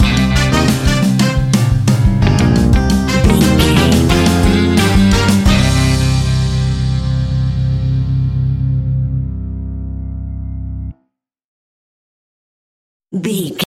Aeolian/Minor
flamenco
latin
salsa
uptempo
bass guitar
percussion
brass
saxophone
trumpet
fender rhodes
clavinet